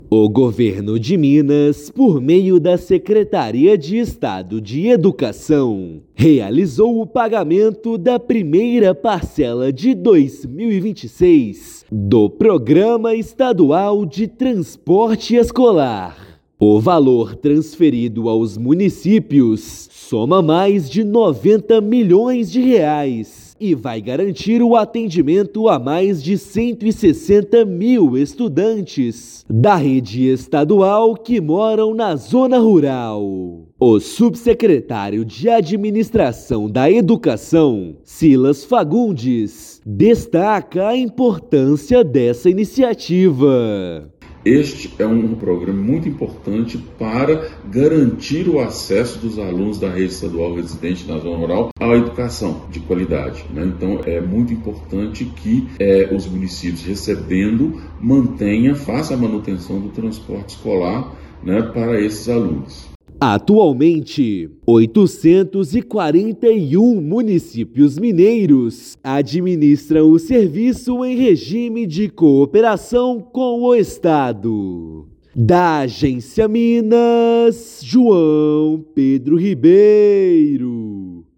Mais de R$ 90 milhões foram transferidos às prefeituras, que passam a integrar o Sistema Transcolar Rural. Ouça matéria de rádio.